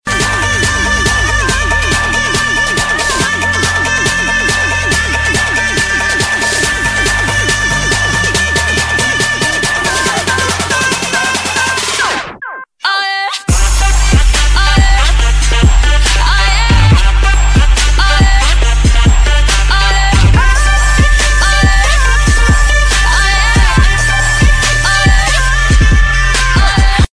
Dance Ringtones